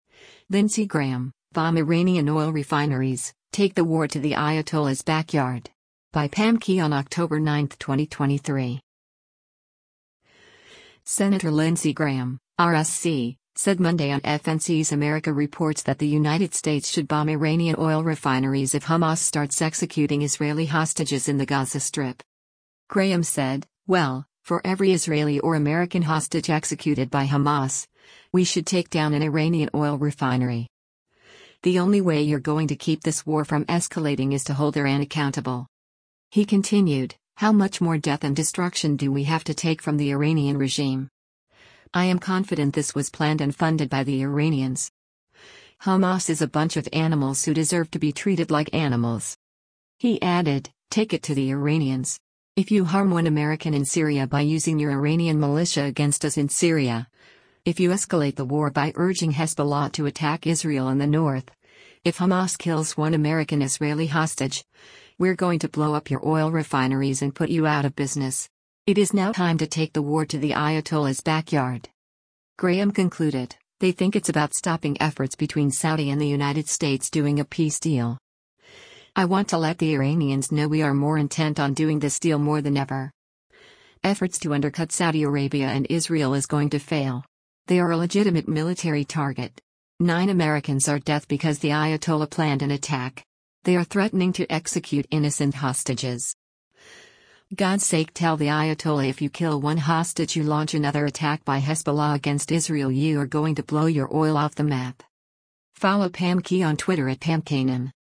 Senator Lindsey Graham (R-SC) said Monday on FNC’s “America Reports” that the United States should bomb Iranian oil refineries if Hamas starts executing Israeli hostages in the Gaza Strip.